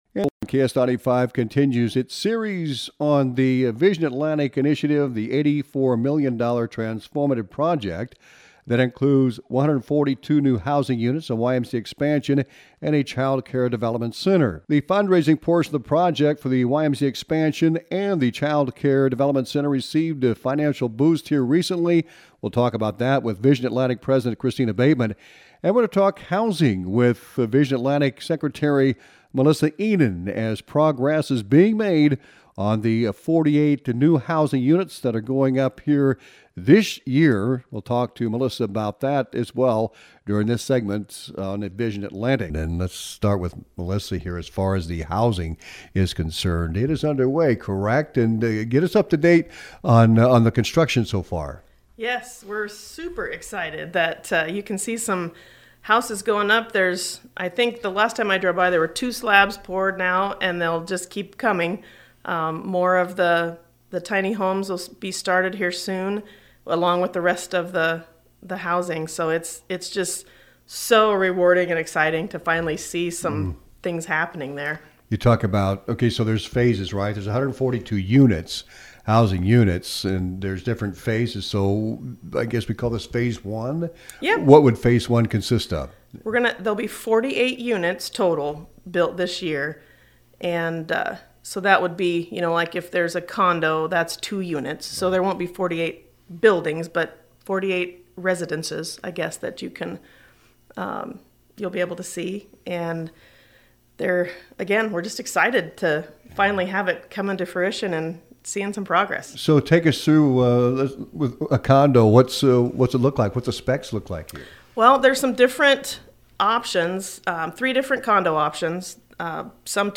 The entire interview is posted below.